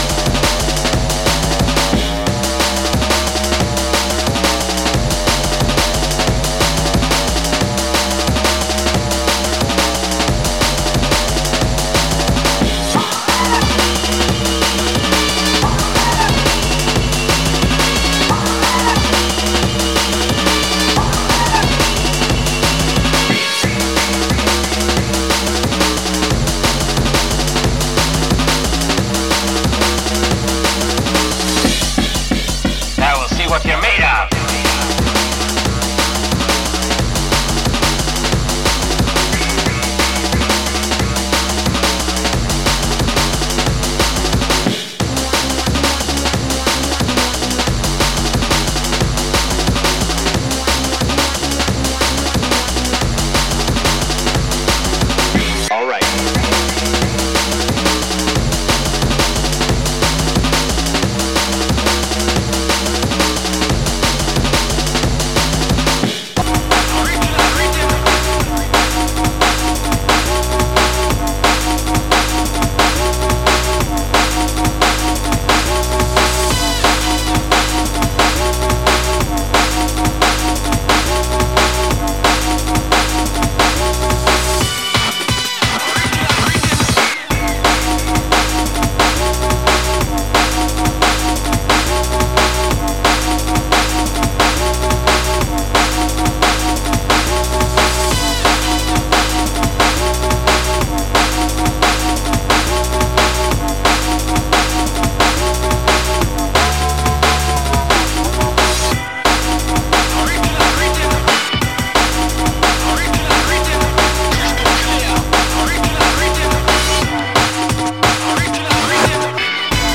Light surface marks and scratches vinyl plays close to VG+